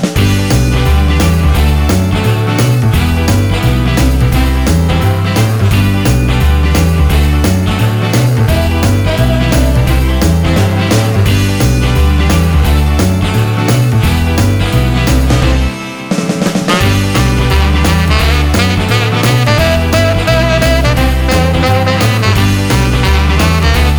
no Backing Vocals Rock 'n' Roll 3:21 Buy £1.50